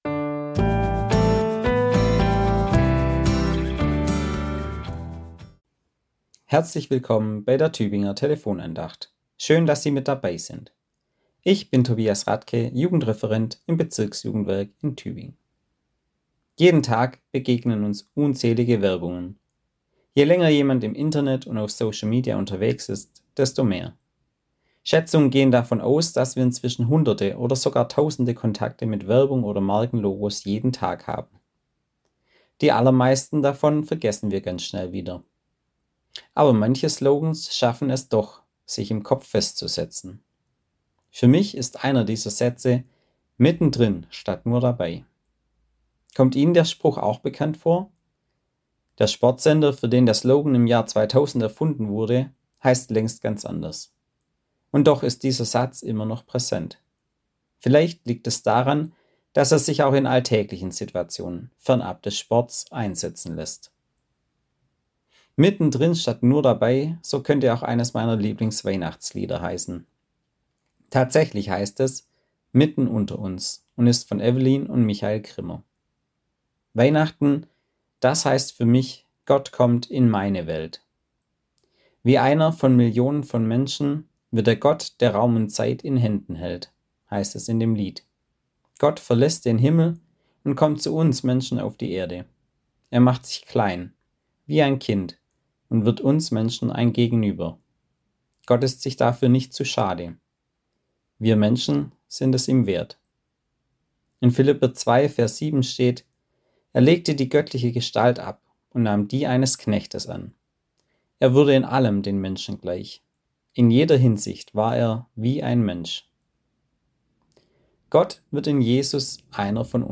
Andacht zur Weihnachtswoche